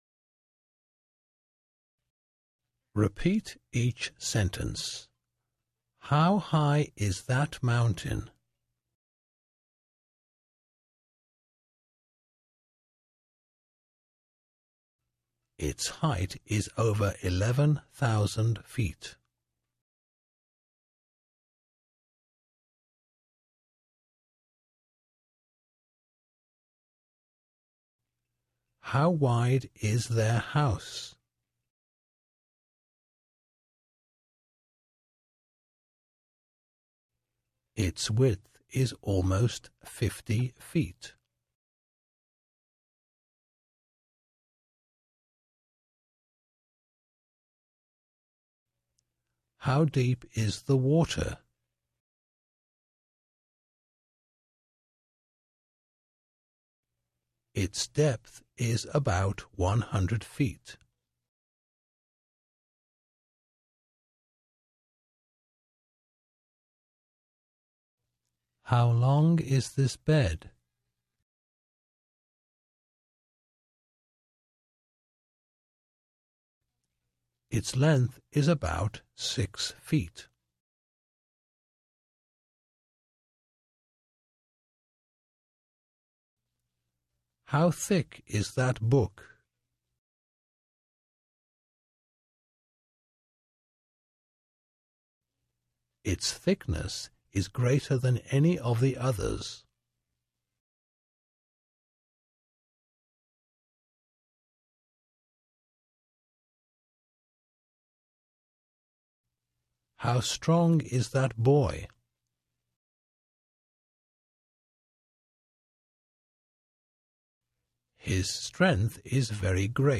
在线英语听力室免费英语课程培训-British Lesson ls14lb的听力文件下载,免费英语课程培训,纯外教口语,初级学习-在线英语听力室